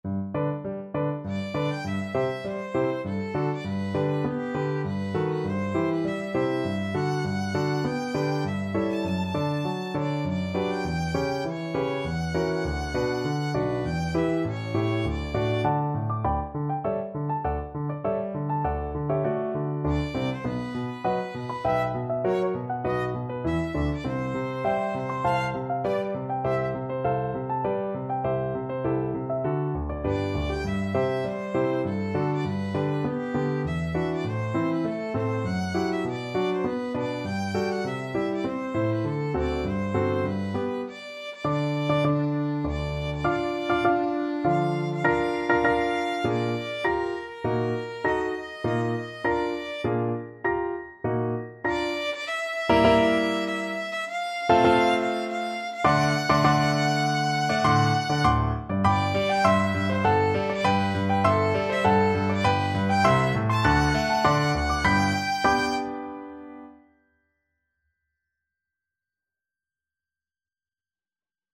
Violin
G major (Sounding Pitch) (View more G major Music for Violin )
3/4 (View more 3/4 Music)
~ = 100 Tempo di Menuetto
D5-A6
Classical (View more Classical Violin Music)